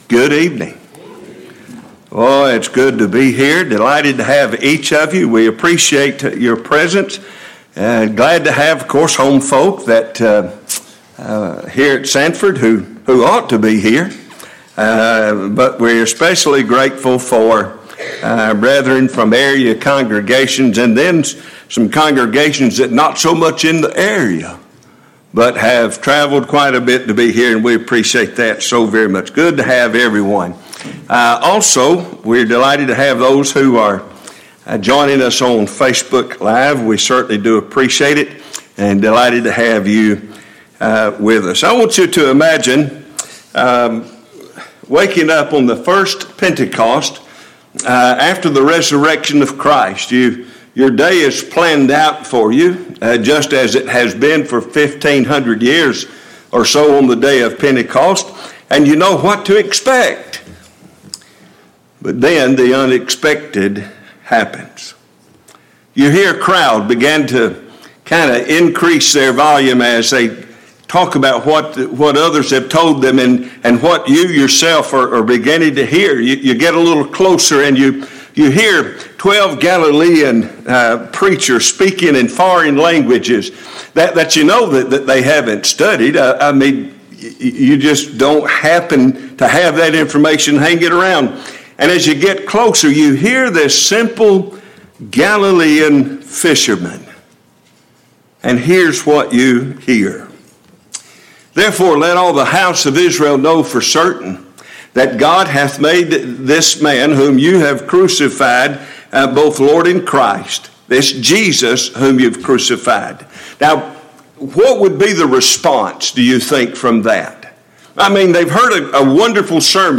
Passage: Acts 2:36-47 Service Type: Gospel Meeting